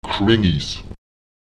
Lautsprecher kenes [Èk¨ENes] die Provinzen